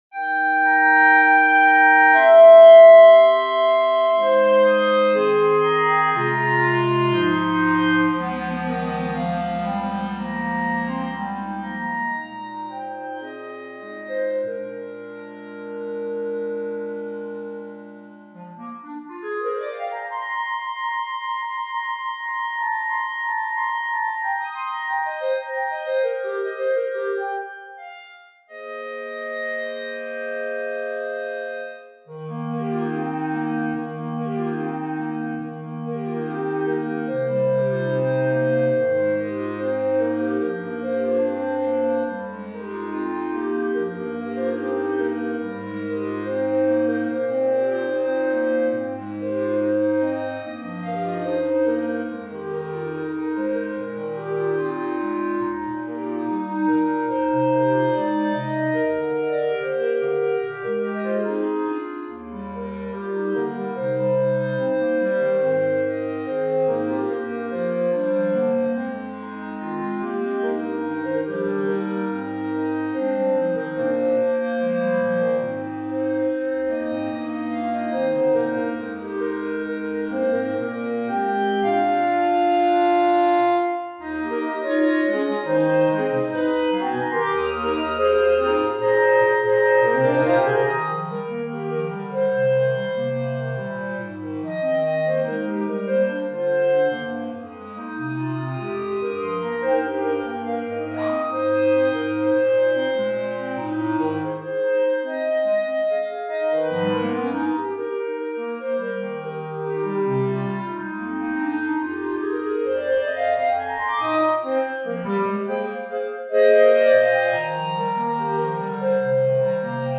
B♭ Clarinet 1 B♭ Clarinet 2 B♭ Clarinet 3 Bass Clarinet
单簧管四重奏
童谣